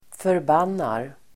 Uttal: [förb'an:ar]